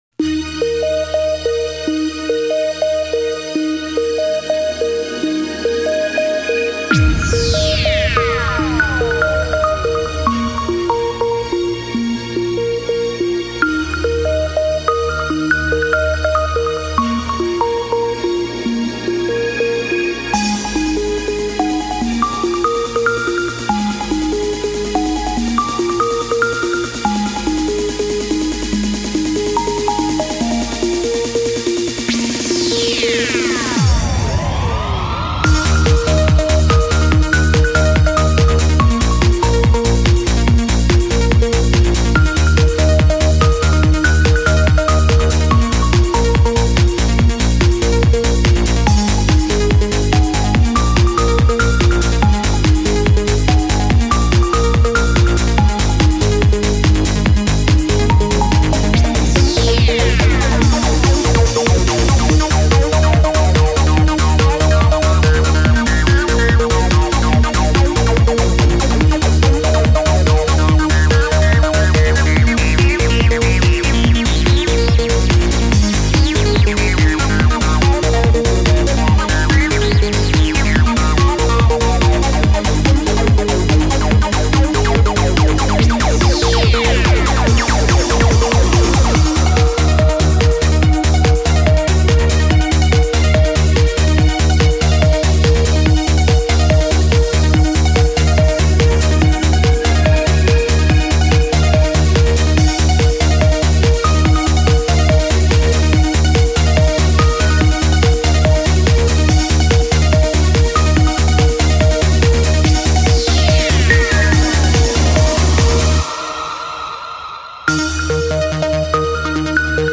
2. Музыкальный фрагмент (